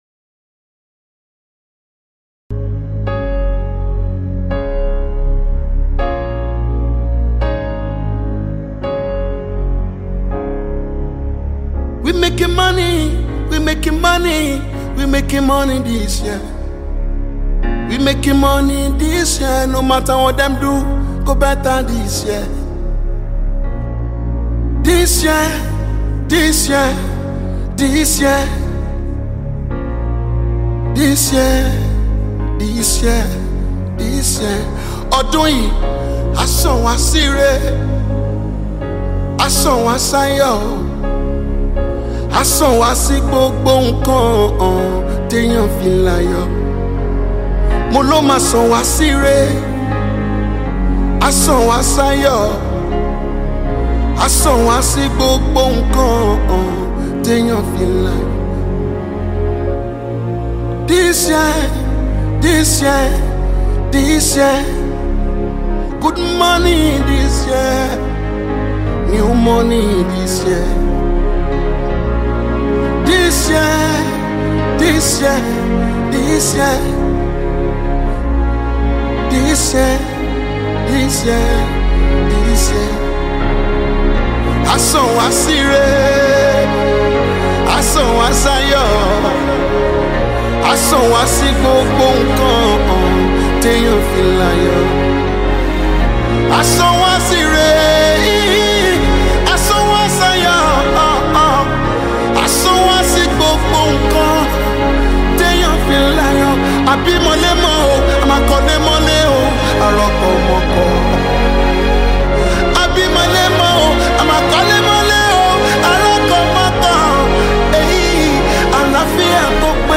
Gospel Songs Mp3 Free Download...